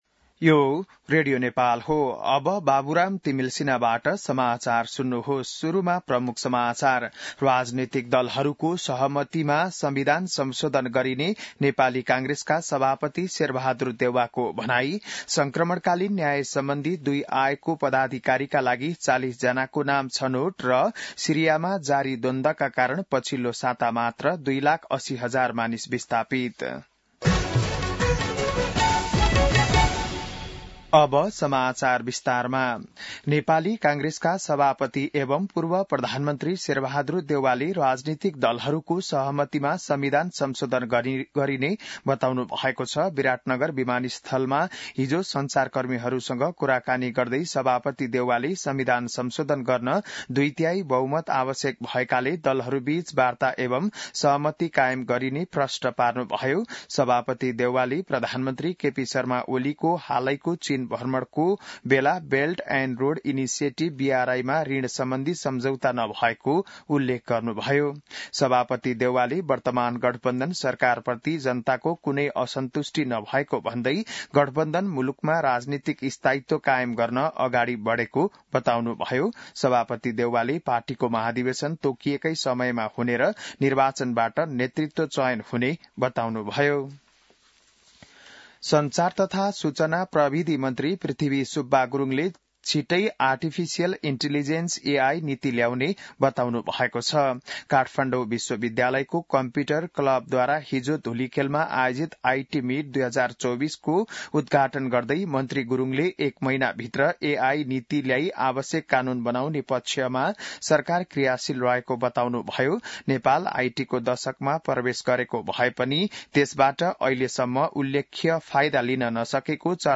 बिहान ९ बजेको नेपाली समाचार : २३ मंसिर , २०८१